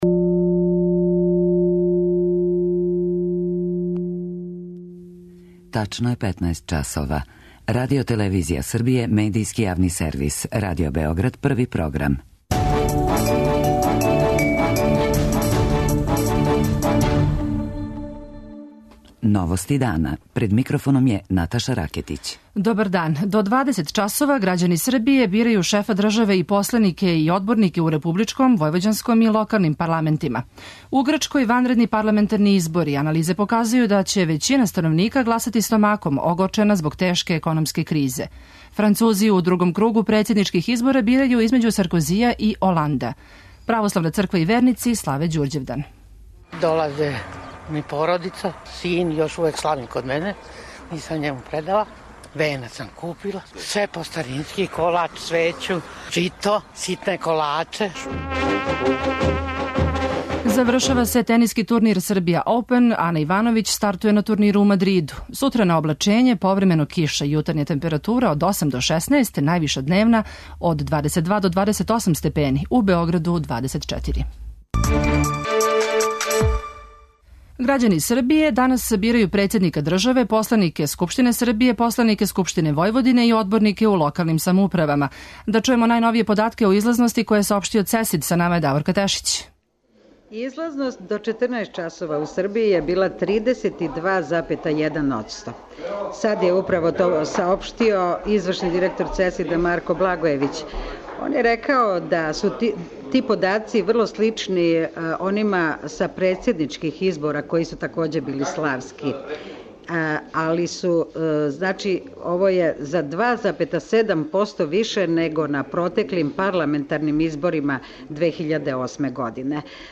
Како протиче гласање, сазнаћете од наших репотрера који се јављају се гласачких места широм Србије, из РИК-а, и ЦЕСИД-а.